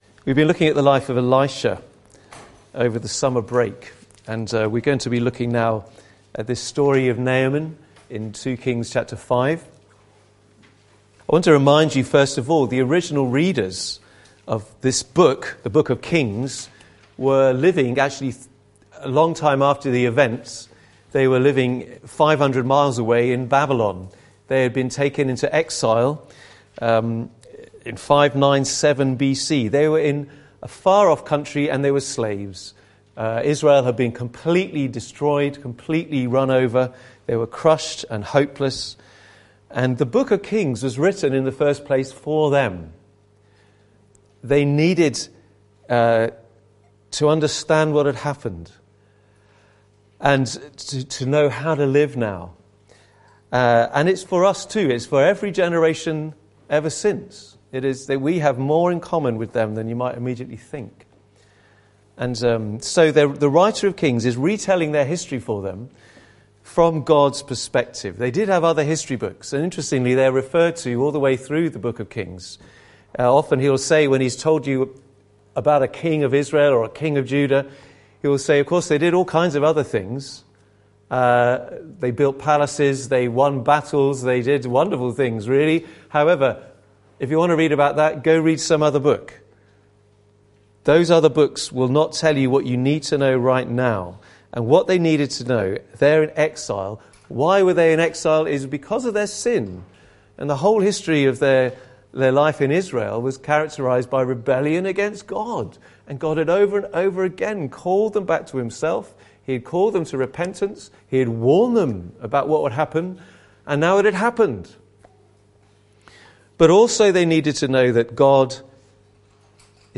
Life & Times of Elisha Passage: 2 Kings 5:1-27 Service Type: Sunday Morning « Another Day